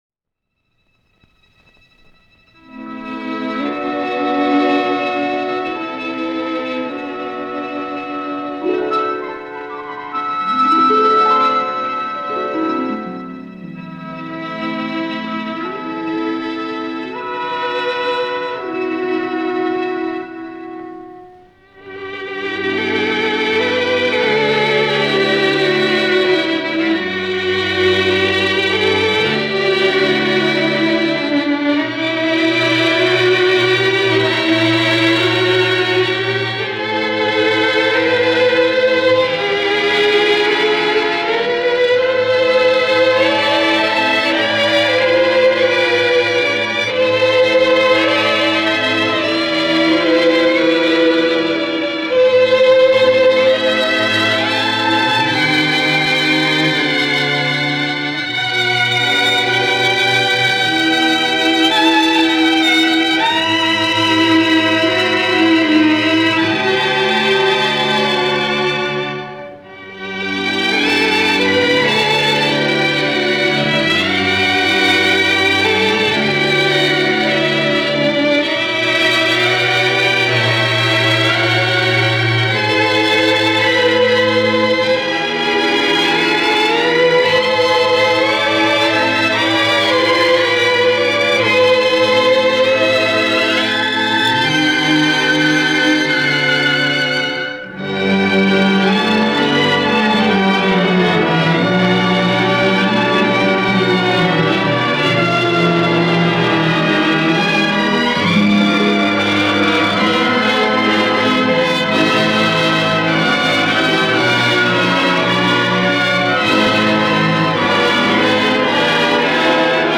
Музыка кино